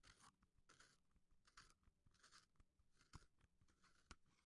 描述：录制在隔音棚内。
Tag: 土豆 芯片 小吃 口香糖 嘎吱嘎吱